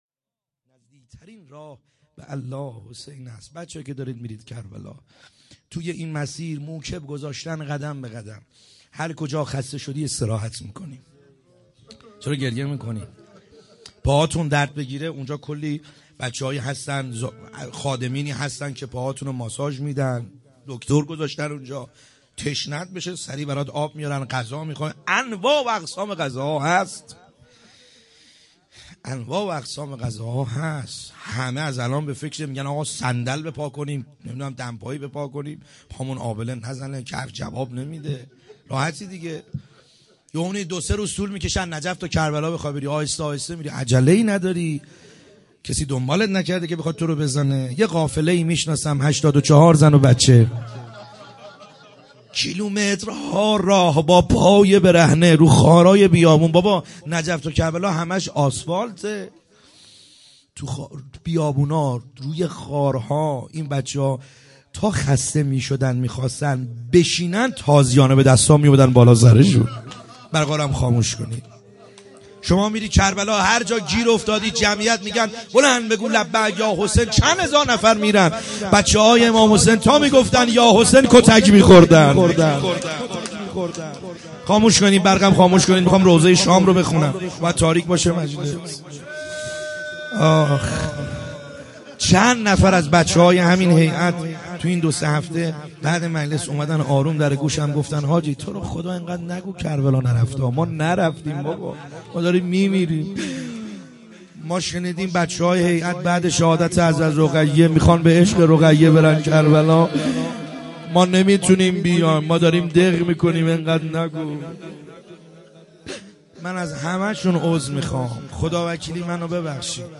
خیمه گاه - بیرق معظم محبین حضرت صاحب الزمان(عج) - روضه | مصائب شام و اسارت